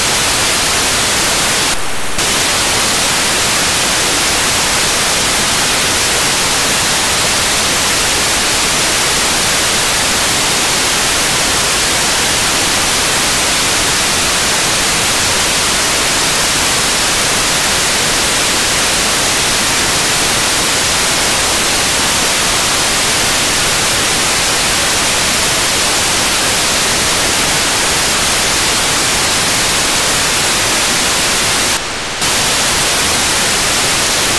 The following downlink frequency has been reported: 437.750 MHz in GMSK 2400 + 9600 bps.